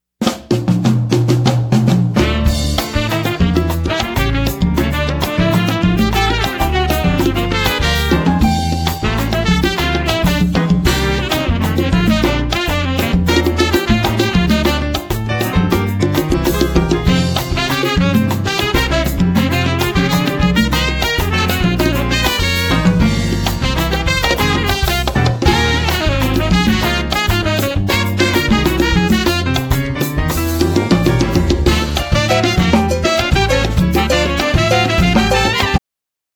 saxes & flute
trumpet
piano
bass
drums
Latin jazz